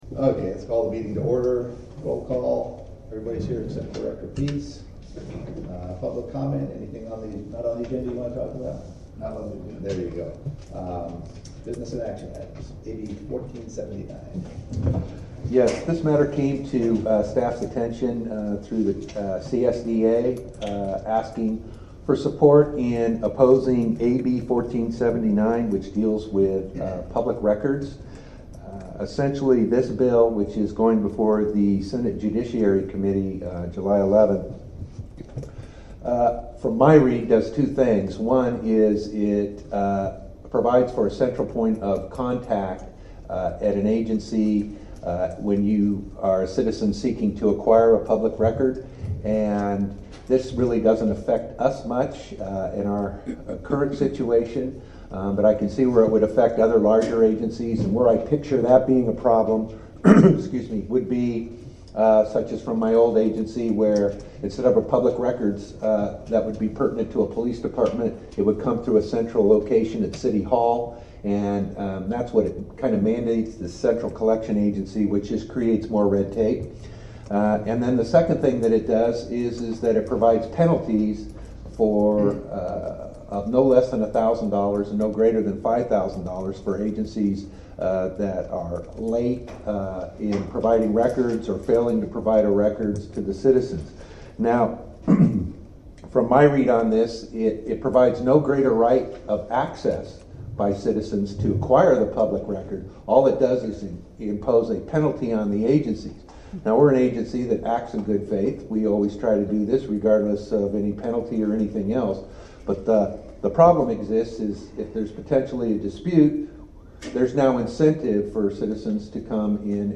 The Town of Discovery Bay CSD meets twice monthly on the first and third Wednesday of each month at 7:00 p.m. at the Community Center located at 1601…
Board of Directors Meeting